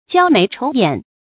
焦眉愁眼 jiāo méi chóu yǎn 成语解释 形容忧虑愁苦的表情。